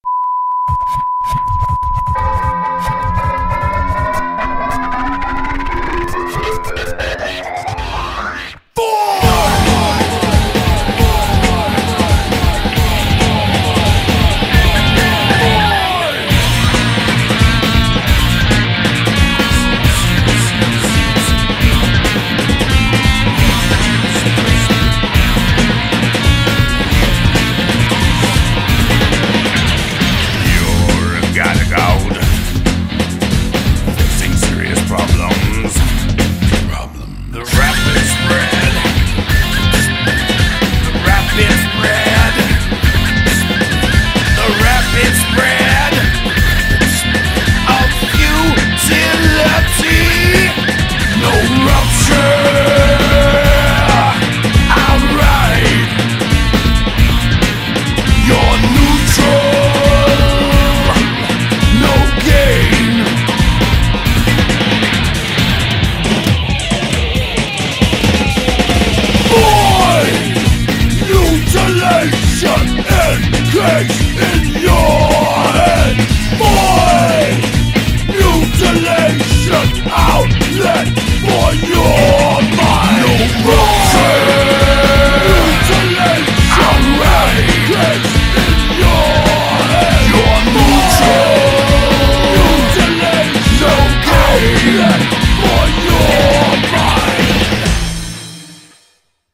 BPM135
Audio QualityPerfect (High Quality)
Its true bpm is 135.7, made sure to dodge a bullet there!